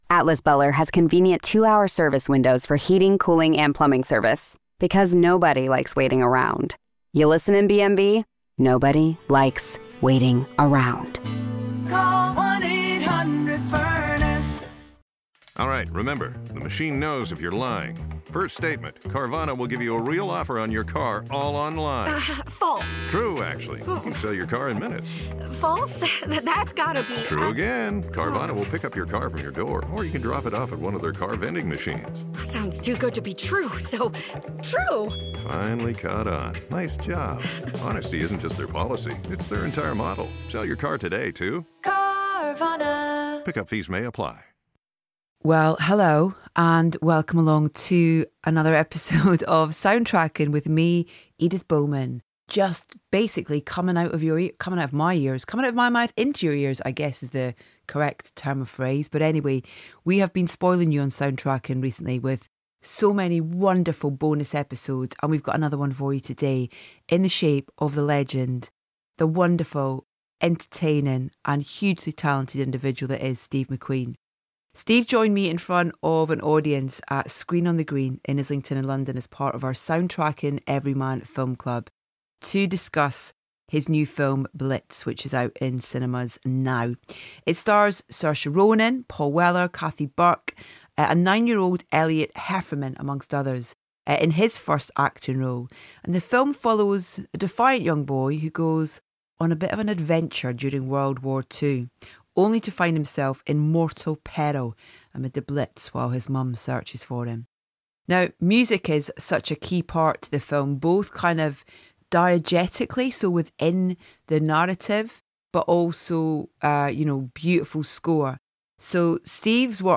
Steve joined Edith in front of an audience at Screen On The Green in Islington, London, as part of our Soundtracking Everyman film club to discuss his new film, Blitz.